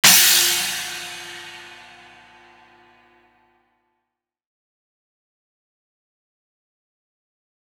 Crashes & Cymbals
Crash Groovin 1.wav